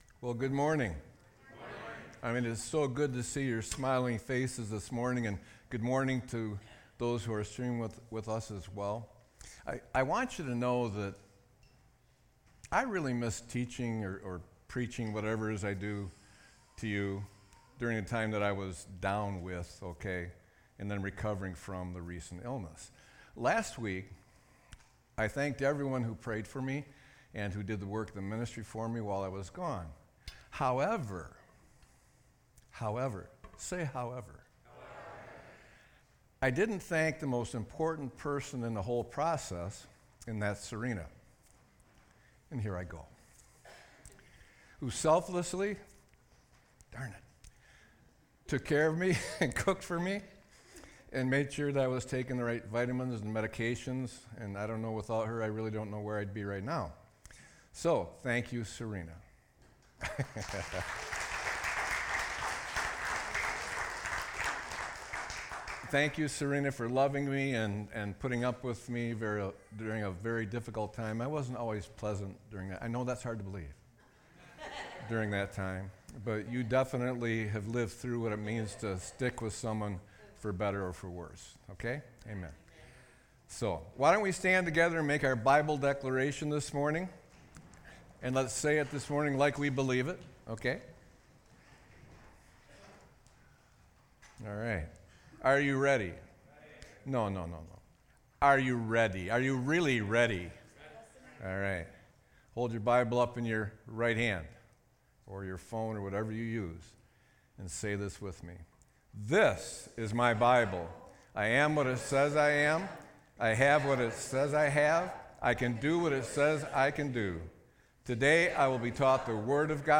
Sermon-9-08-24.mp3